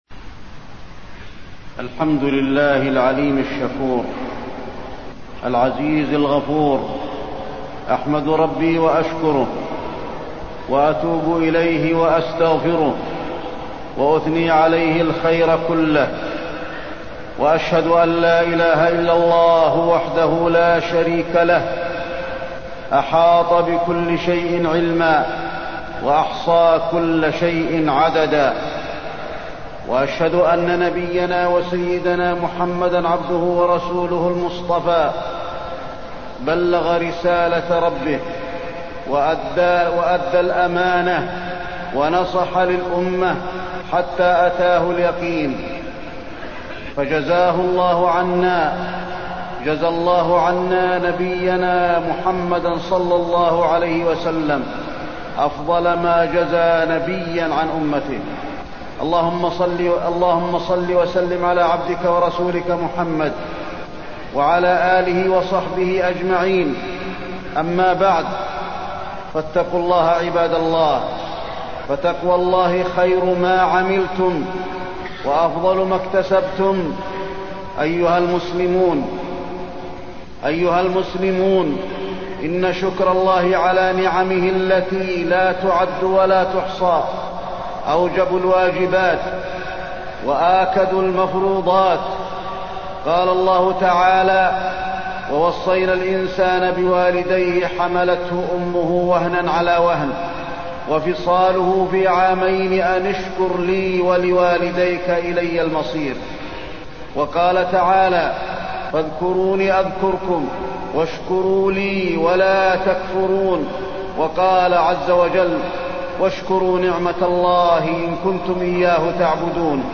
تاريخ النشر ٩ شوال ١٤٢٣ هـ المكان: المسجد النبوي الشيخ: فضيلة الشيخ د. علي بن عبدالرحمن الحذيفي فضيلة الشيخ د. علي بن عبدالرحمن الحذيفي الشكر